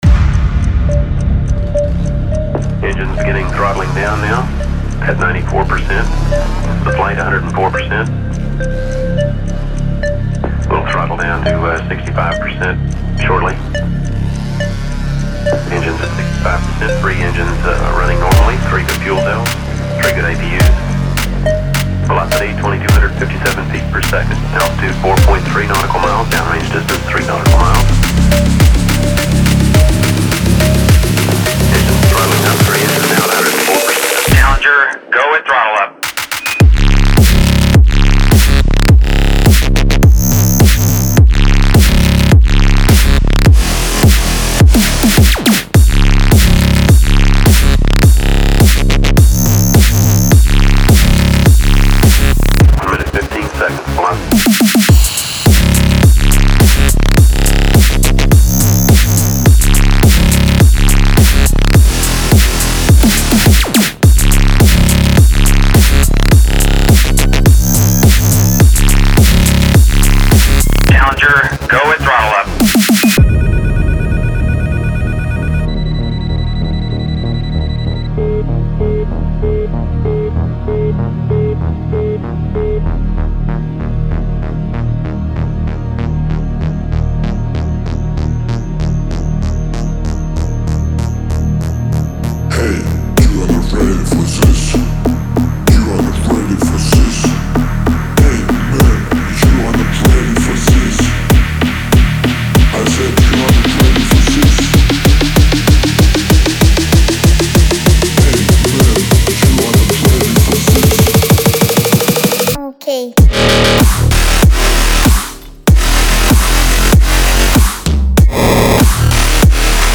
DubstepEDM